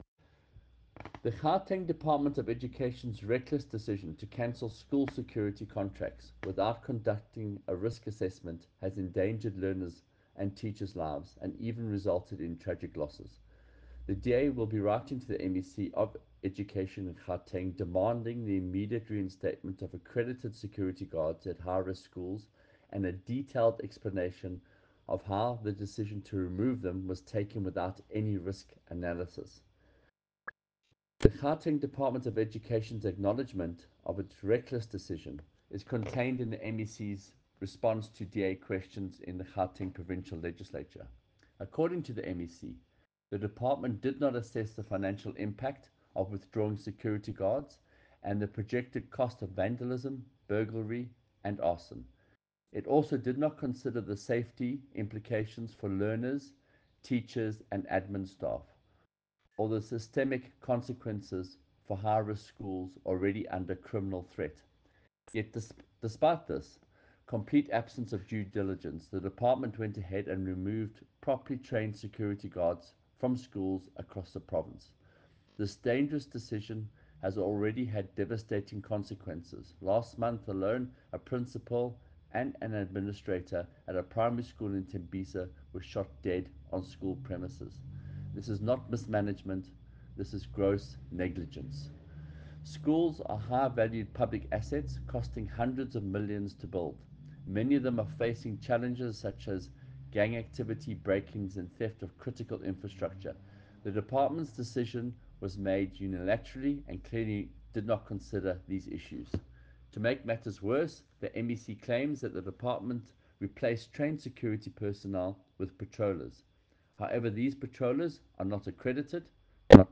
soundbite by Michael Waters MPL.